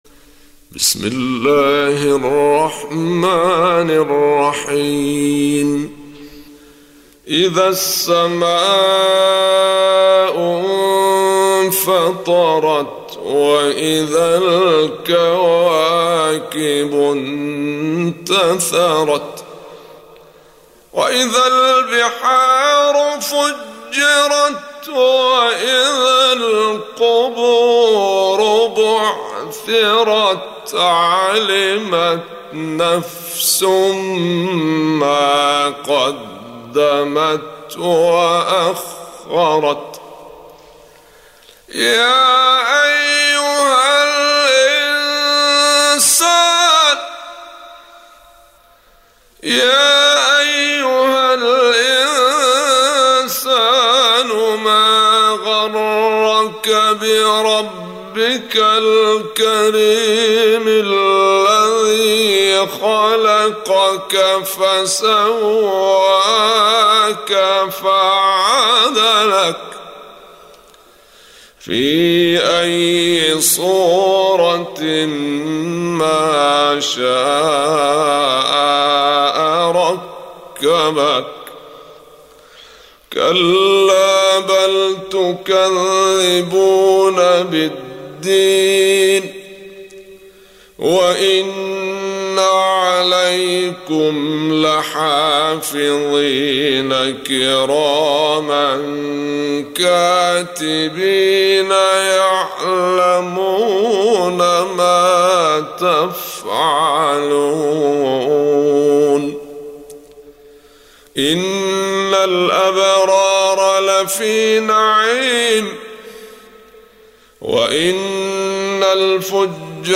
Surah Sequence تتابع السورة Download Surah حمّل السورة Reciting Murattalah Audio for 82. Surah Al-Infit�r سورة الإنفطار N.B *Surah Includes Al-Basmalah Reciters Sequents تتابع التلاوات Reciters Repeats تكرار التلاوات